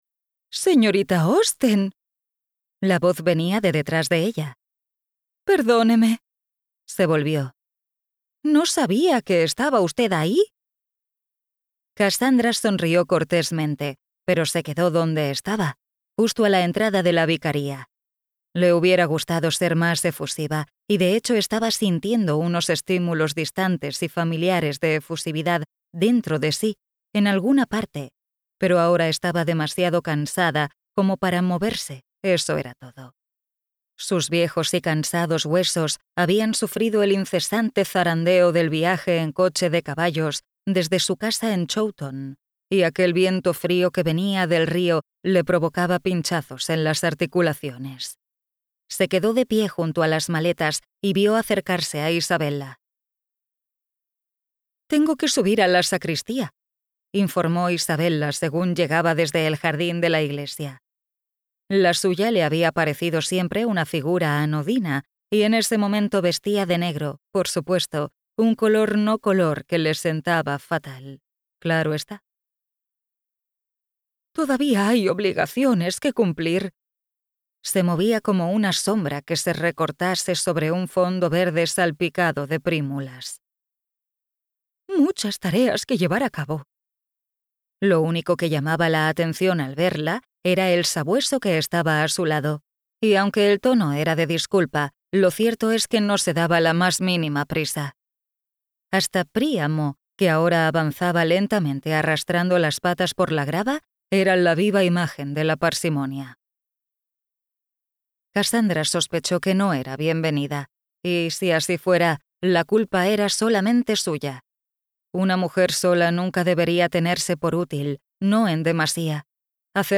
Audiolibro Miss Austen